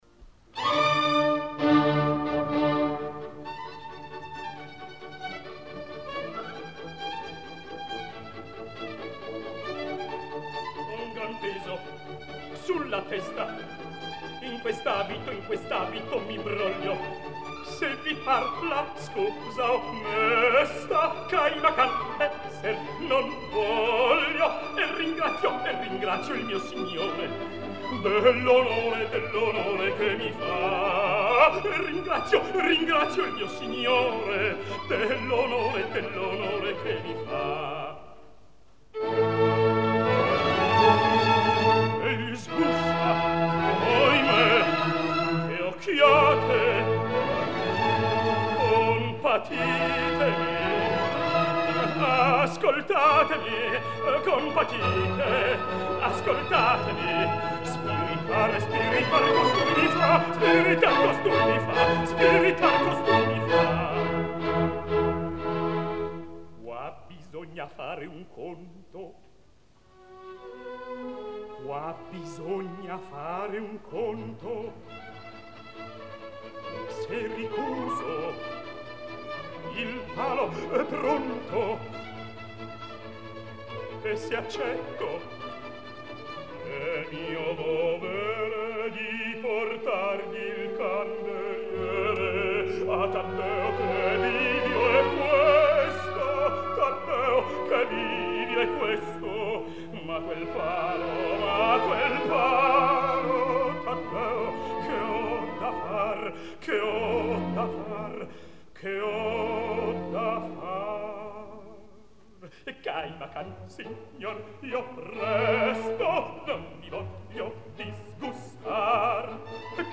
замечательная ария Таддео, комического персонажа оперы "Итальянка в Алжире" в исполнении Сесто Брускантини.